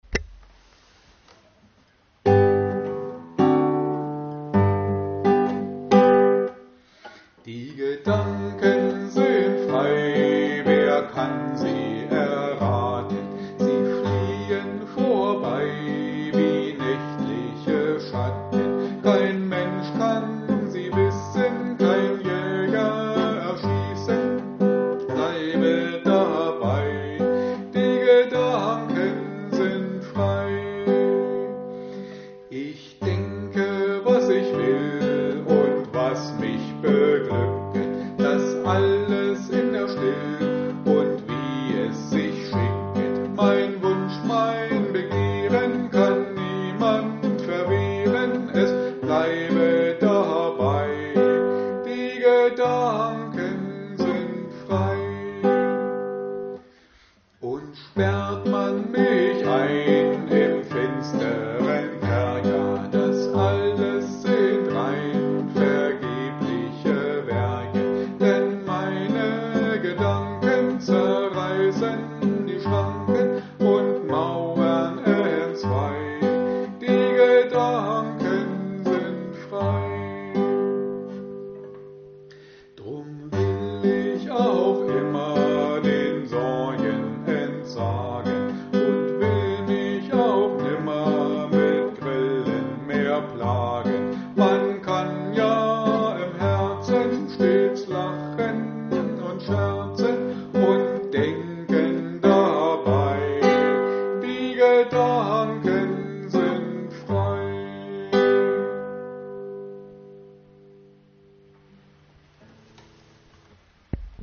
Da auch wir zur Zeit in vielen Seniorenheimen nicht arbeiten können und folglich auch keine Gruppen stattfinden, haben wir Ihnen ein kleines Liederbuch mit dazugehöriger Begleitung zusammengestellt.